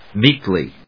音節méek・ly 発音記号・読み方
/ˈmikli(米国英語), ˈmi:kli:(英国英語)/